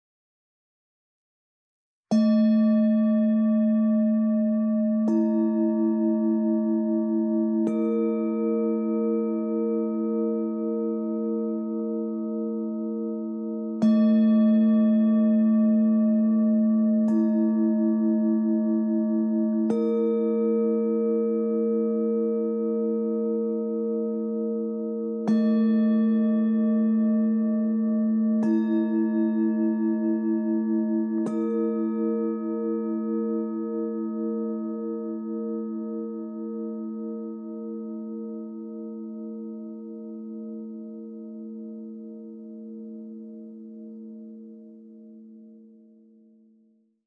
These singing bowls are raw and natural with power and energy.